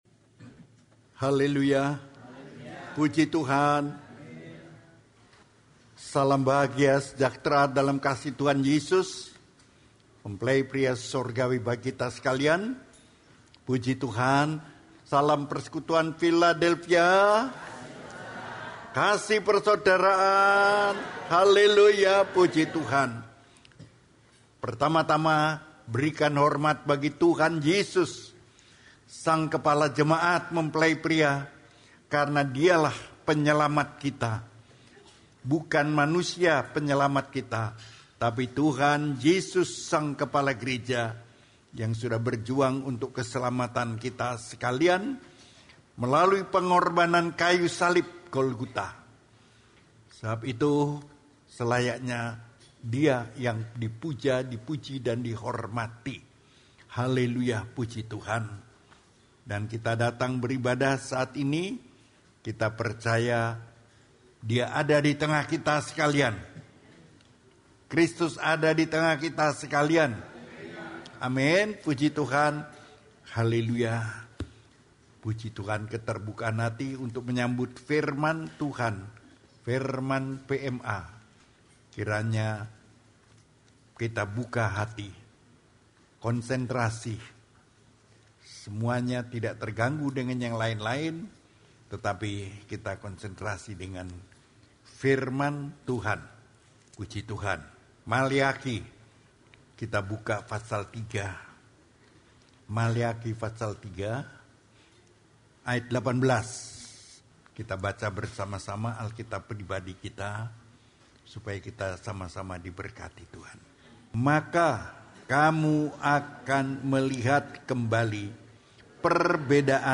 Khotbah (Audio)
Khotbah Pengajaran